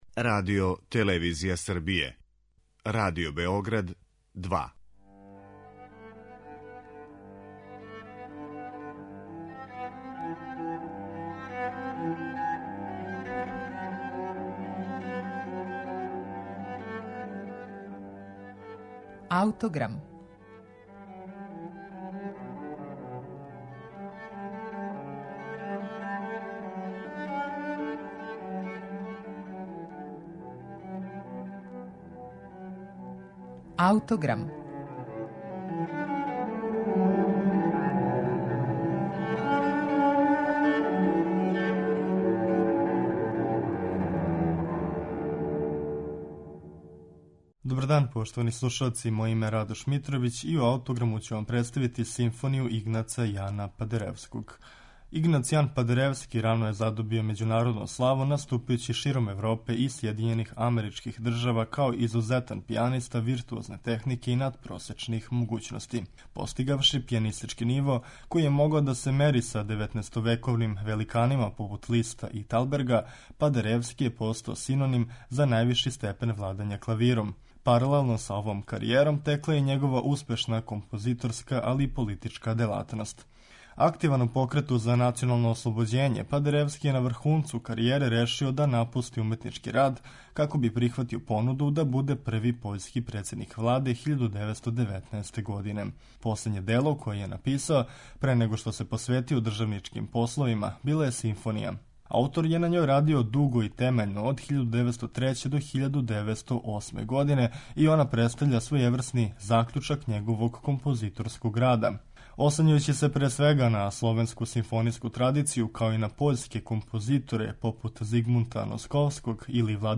Монументална Симфонија "Полонија" Игнаца Падеревског јесте последње дело овог пољског композитора, пијанисте и државника. Ова композиција, патриотске поруке, заокружује његово плодно стваралаштво.
Симфонију Полонија Игнаца Падеревског слушаћемо у извођењу Симфонијског оркестра Помераније и Богдана Водичког.